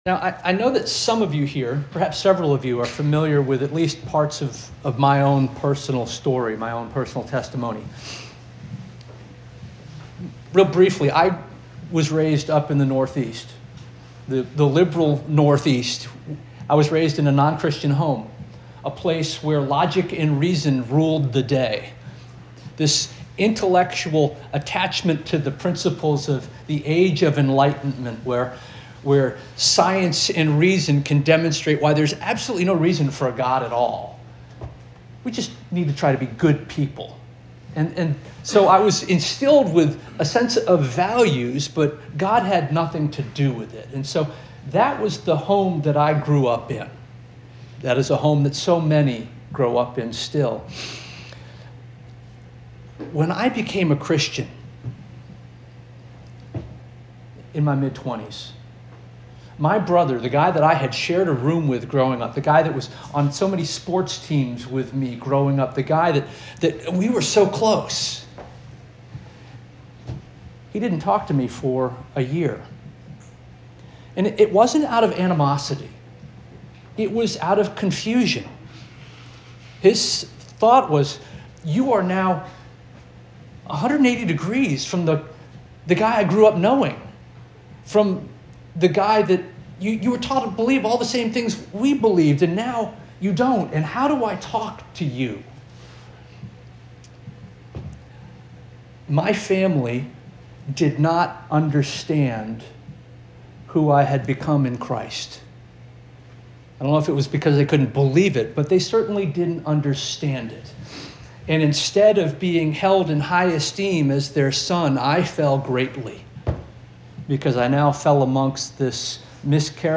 February 8 2026 Sermon - First Union African Baptist Church